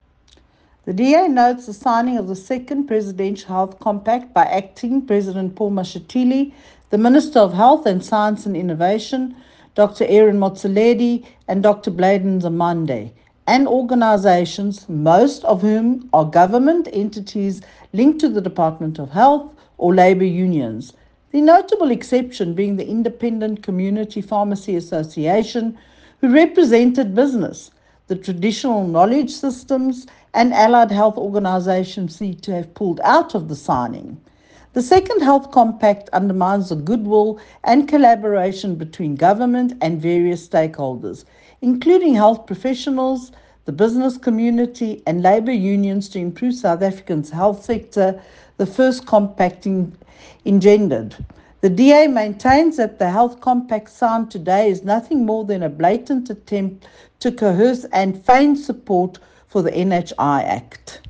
soundbite by Michele Clarke MP